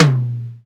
TOM XC.TOM07.wav